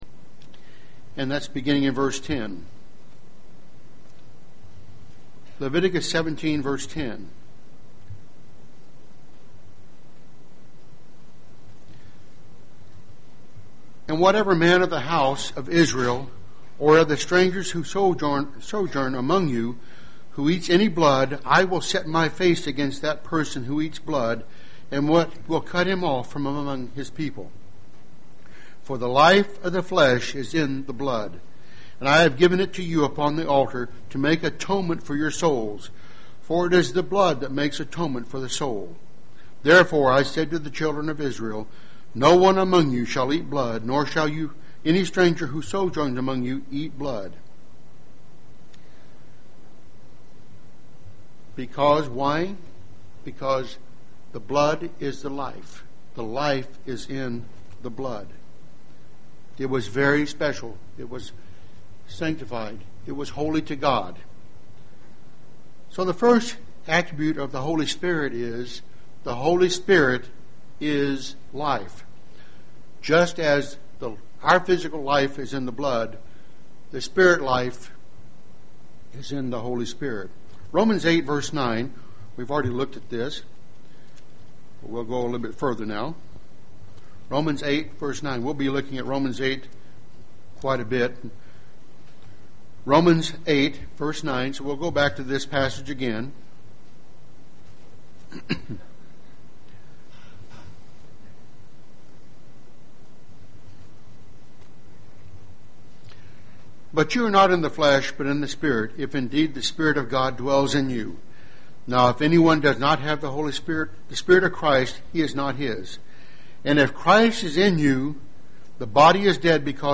UCG Sermon Studying the bible?
Given in Dayton, OH